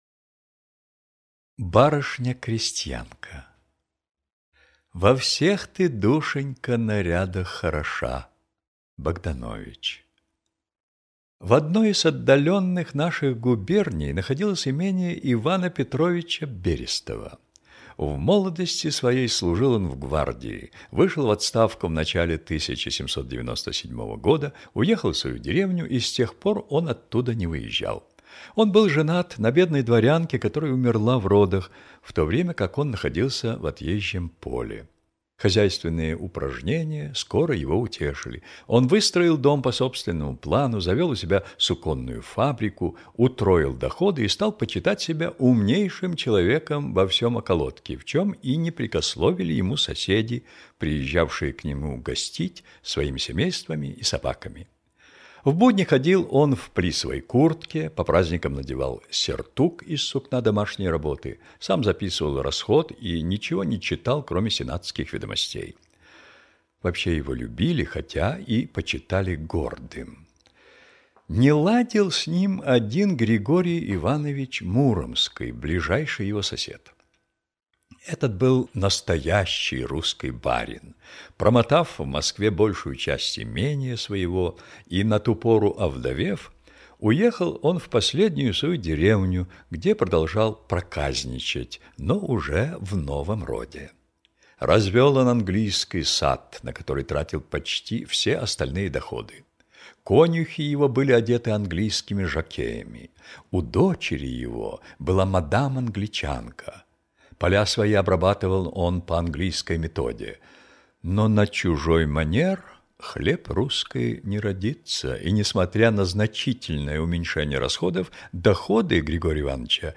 Барышня-крестьянка - аудио повесть Пушкина - слушать онлайн